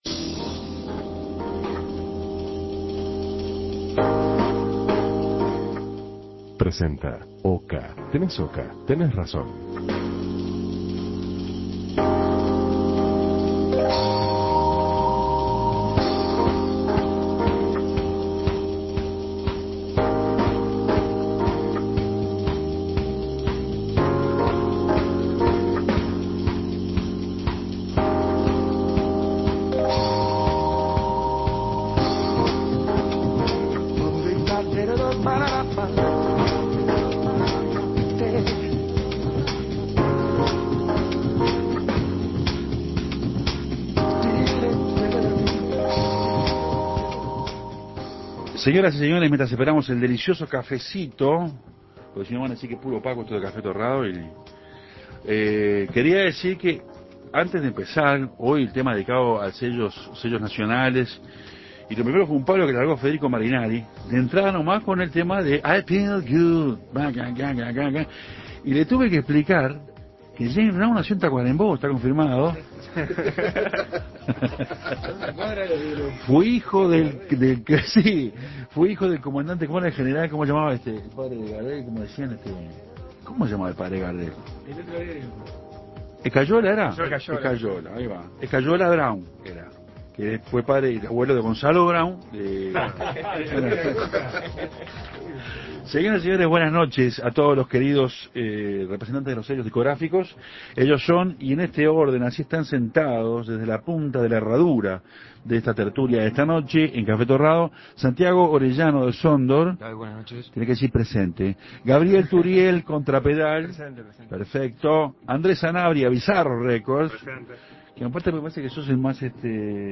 Mesa redonda con protagonistas de la Industria Discográfica